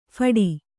♪ phaḍi